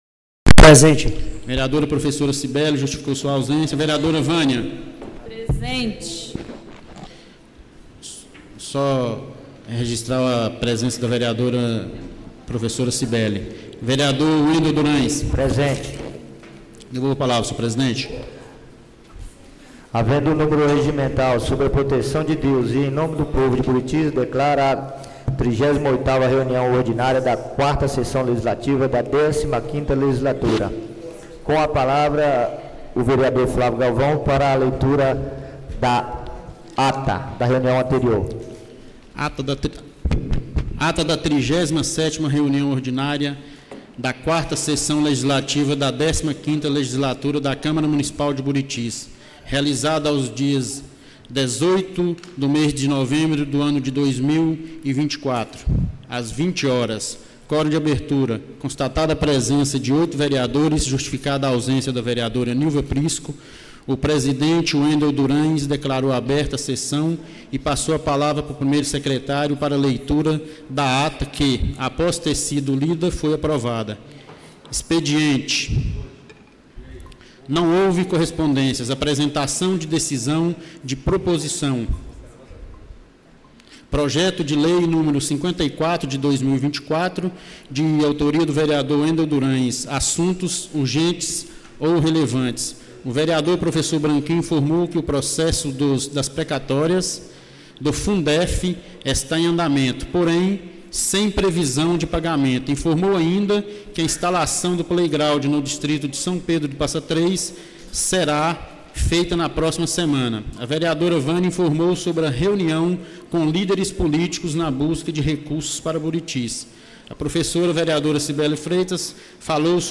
38ª Reunião Ordinária da 4ª Sessão Legislativa da 15ª Legislatura - 25-11-24